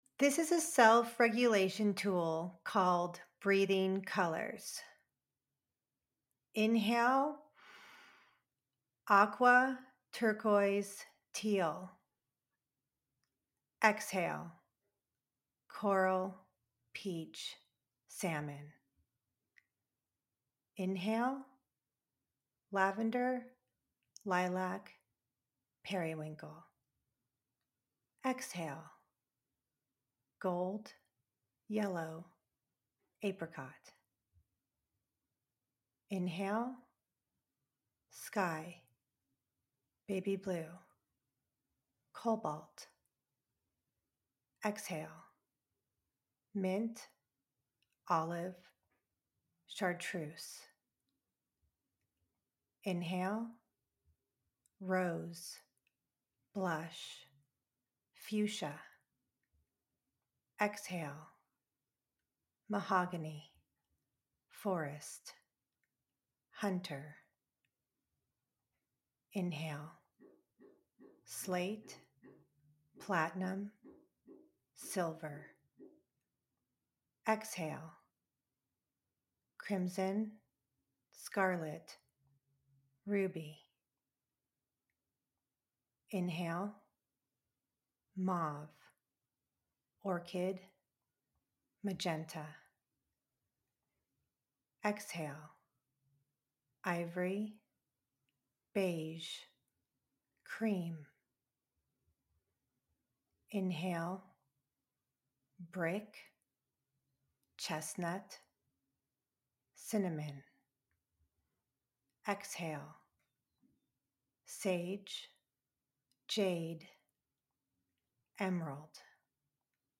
Listen to this 2 minute 'Time Out' Meditation anytime you crave a wave of calm.